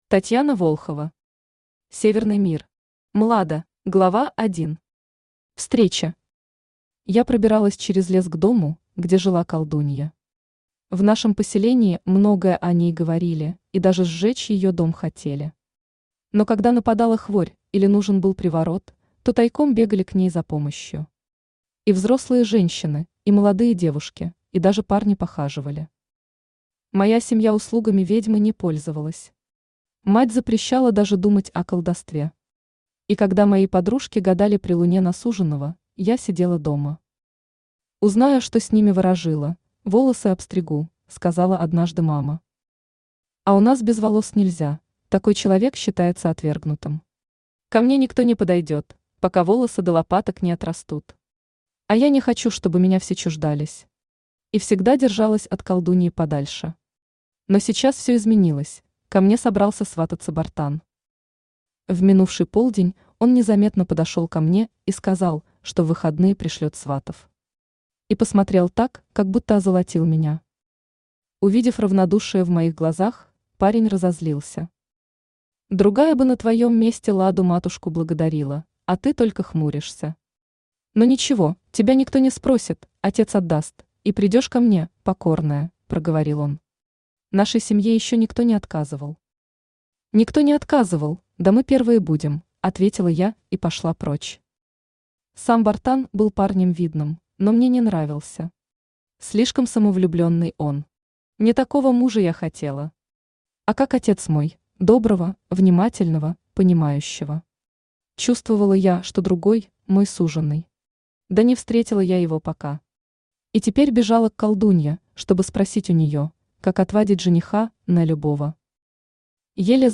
Аудиокнига Северный мир. Млада | Библиотека аудиокниг
Млада Автор Татьяна Волхова Читает аудиокнигу Авточтец ЛитРес.